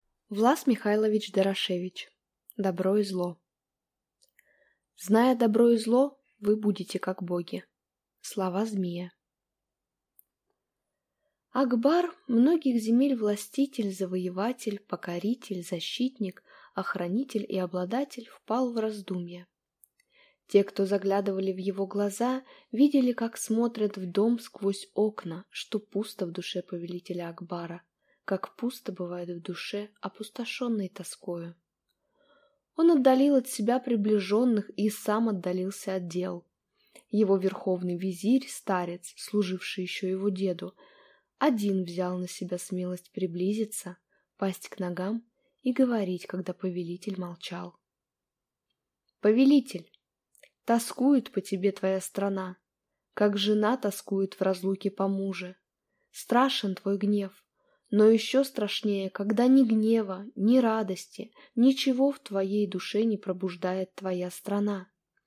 Аудиокнига Добро и зло | Библиотека аудиокниг